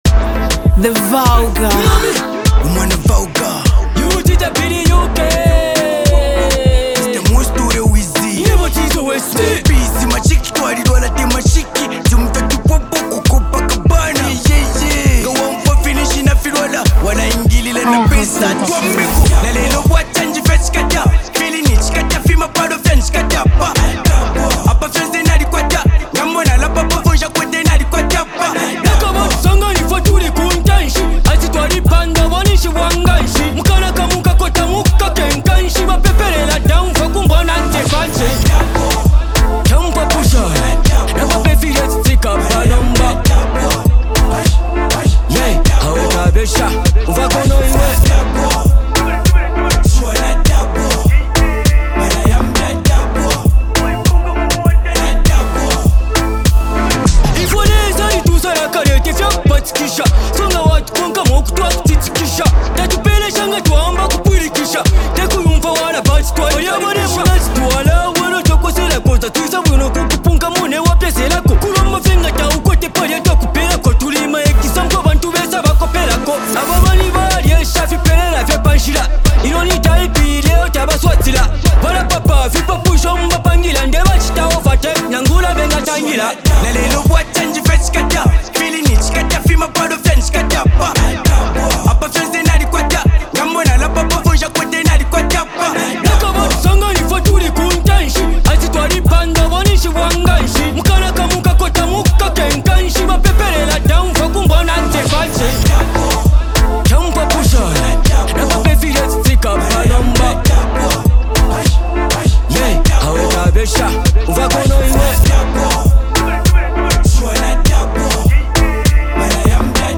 MusicZambian Music